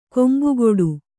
♪ kombugoḍu